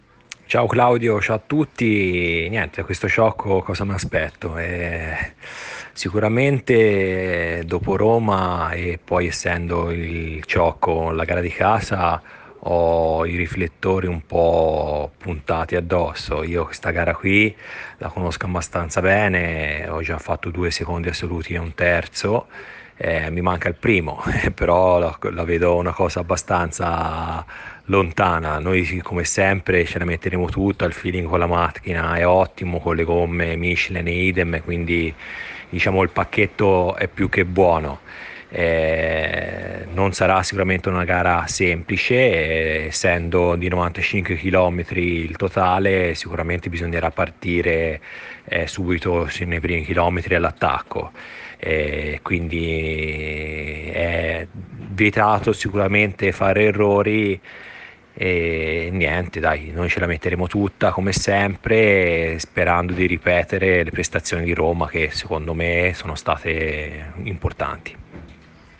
Interviste pre-gara